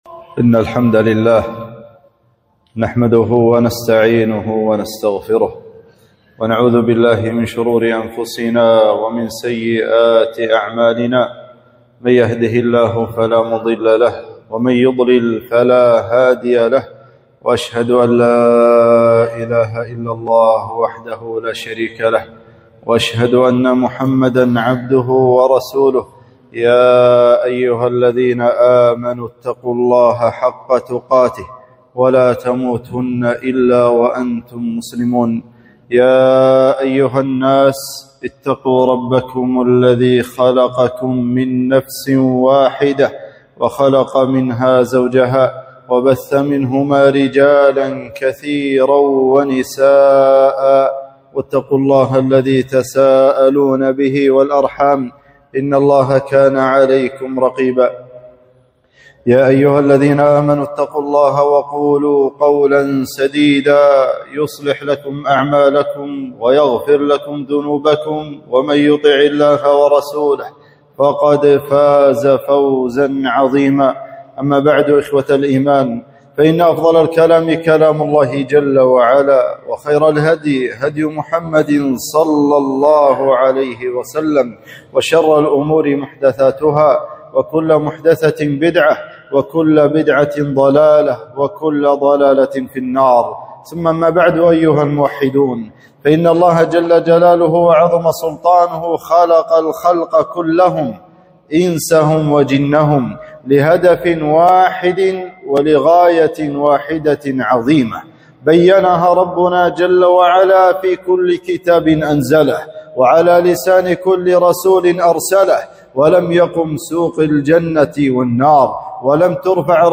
خطبة - أهمية التوحيد